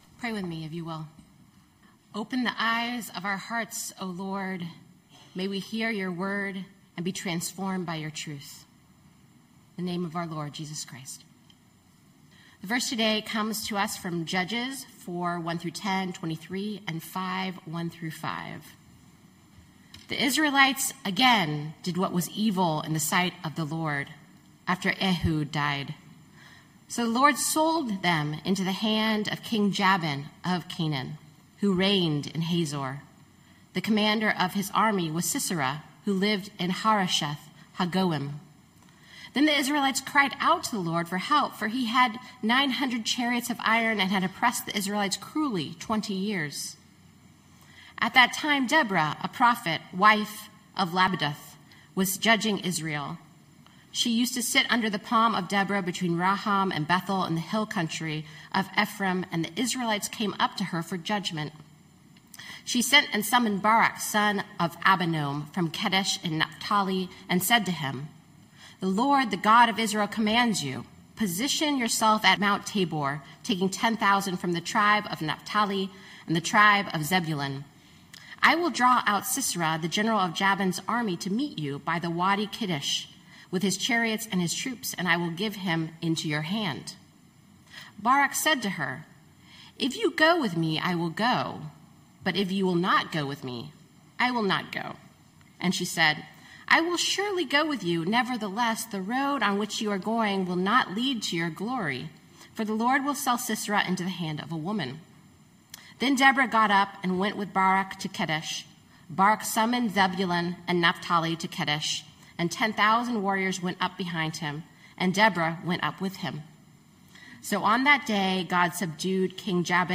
Knox Pasadena Sermons Waiting on the Words of the Prophetess, Pt 2: Deborah Dec 07 2025 | 00:25:46 1x 00:00 / 00:25:46 Subscribe Share Spotify RSS Feed Share Link Embed